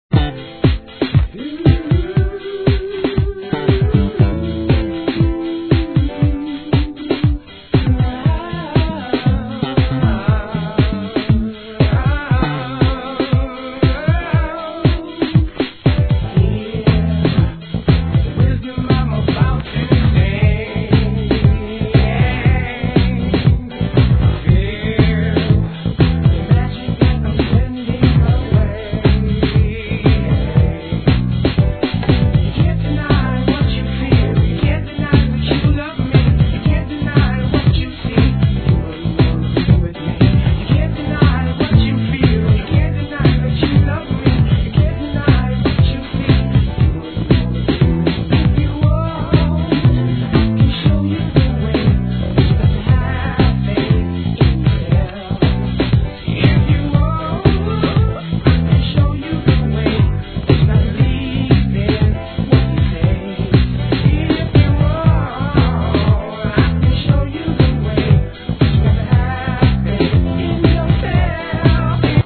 1. HIP HOP/R&B
暖かくソウルフルな男女ヴォーカルを響かせた逸品!